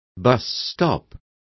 Also find out how paradero is pronounced correctly.